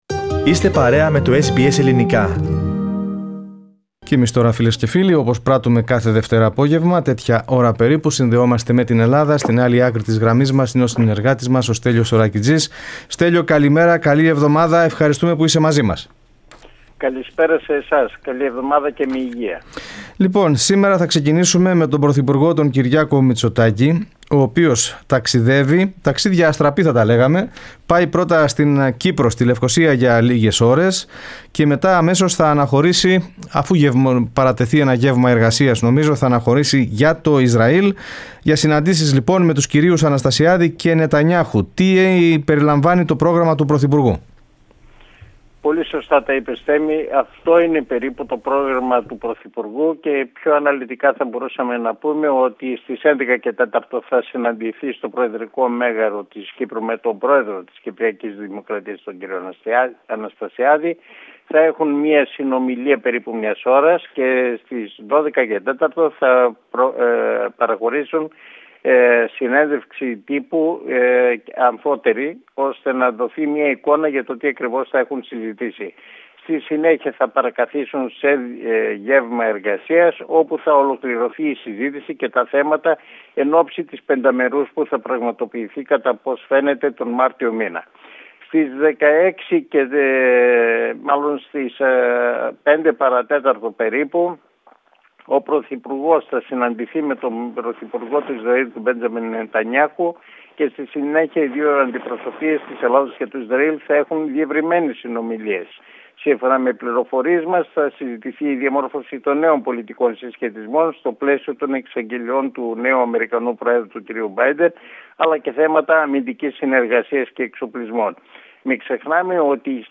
Ψήφος Αποδήμων και Ελληνοτουρκικά μεταξύ των θεμάτων της εβδομαδιαίας ανταπόκρισης από την Αθήνα (8.2.2021)